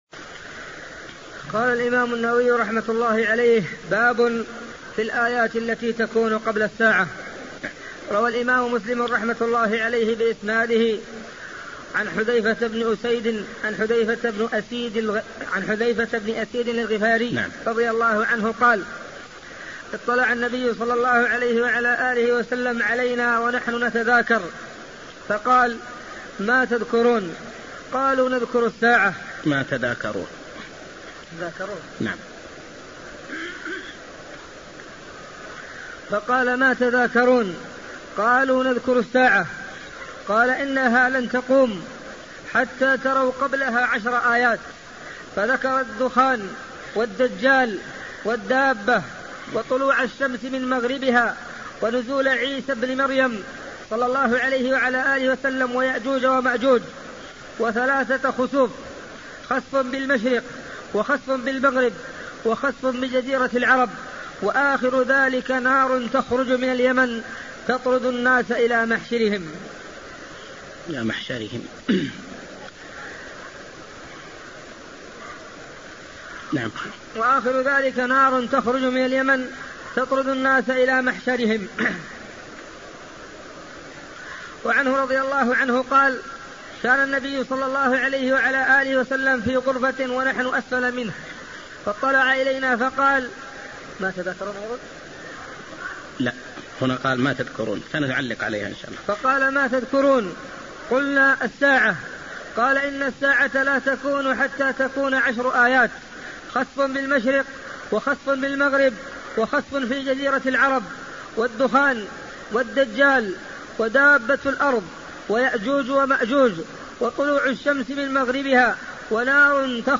صحيح مسلم شرح